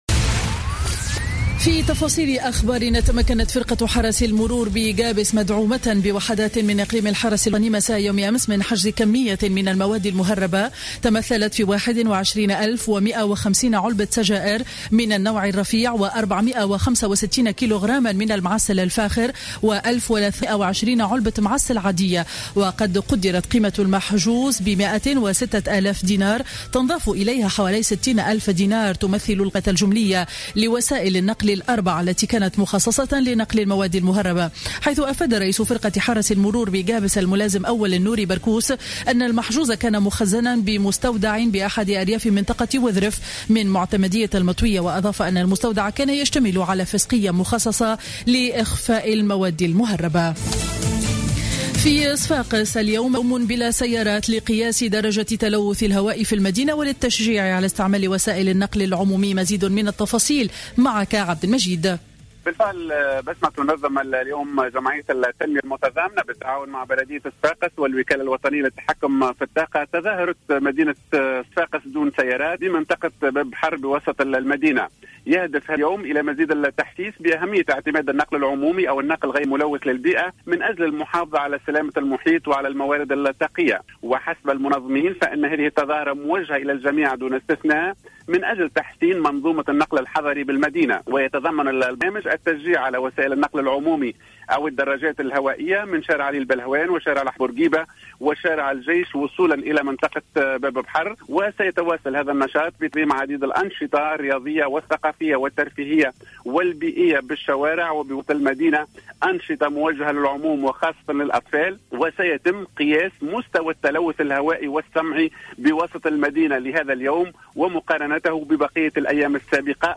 نشرة أخبار منتصف النهار ليوم الأحد 20 سبتمبر 2015